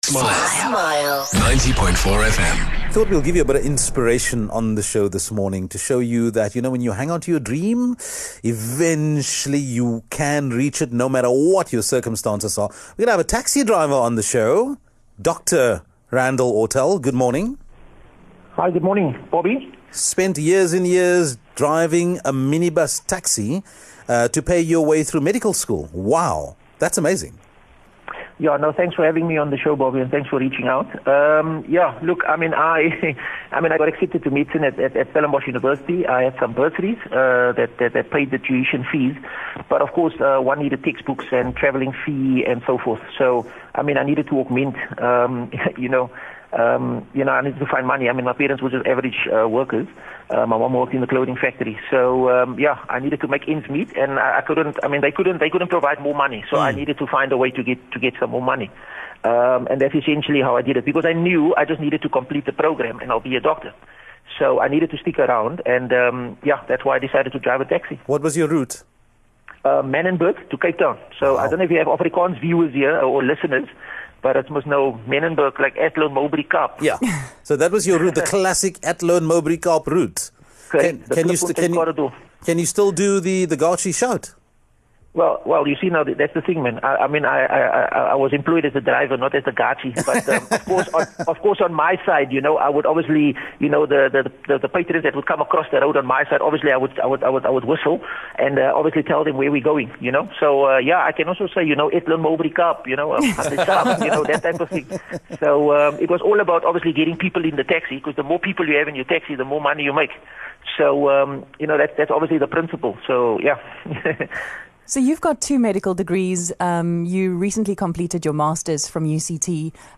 Smile Breakfast gave him a call.